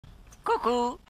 coucou dobby Meme Sound Effect
Category: Movie Soundboard